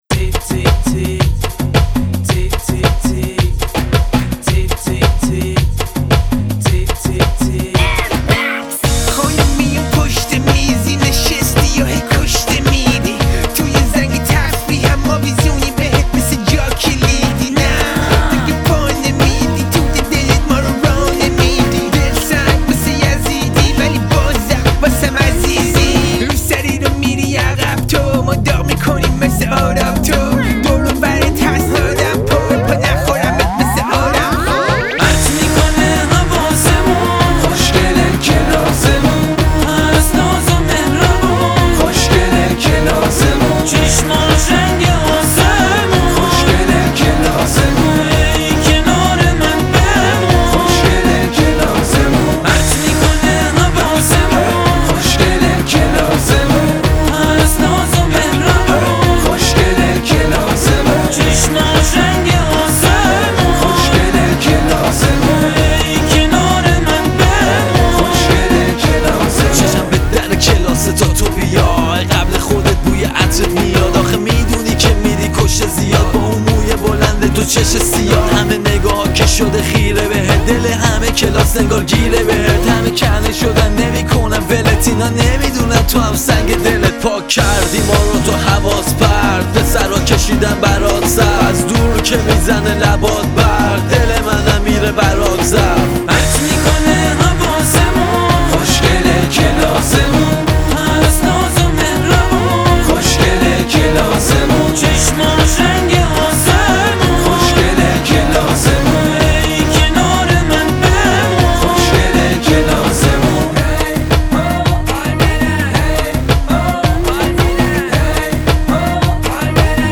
موزیک رپ شاد